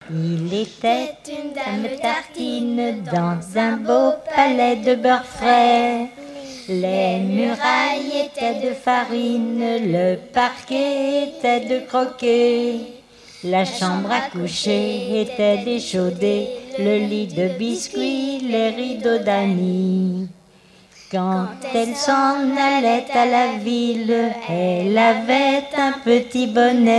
Genre strophique
Festival de la chanson traditionnelle - chanteurs des cantons de Vendée
Pièce musicale inédite